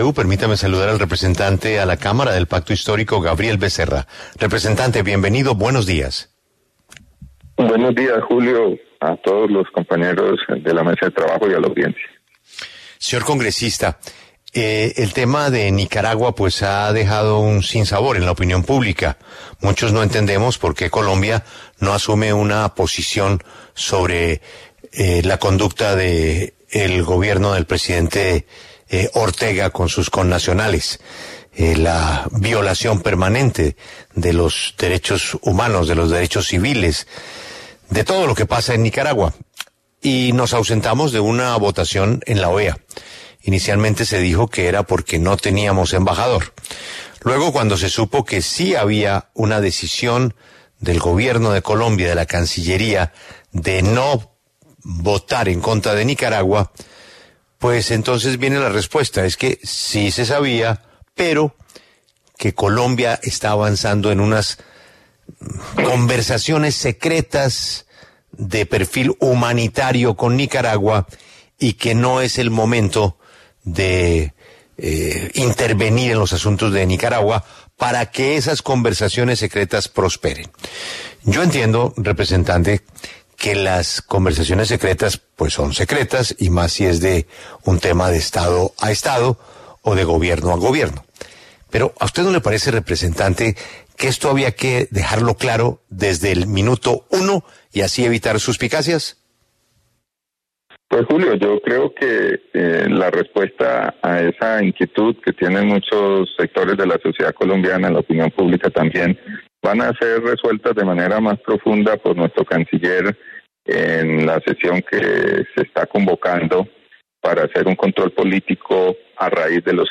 En diálogo con La W, el representante Gabriel Becerra se pronunció sobre el silencio de Colombia frente a las violaciones de derechos humanos del Gobierno de Daniel Ortega en Nicaragua.